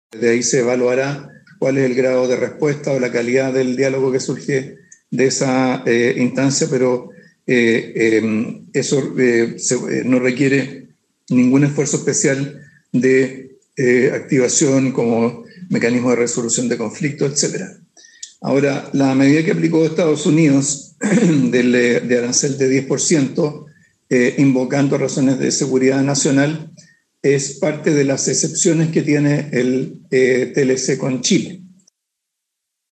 Ahora bien, el ministro de Hacienda, Mario Marcel, usó parte de su punto de prensa ayer jueves en el palacio de La Moneda para referirse al TLC.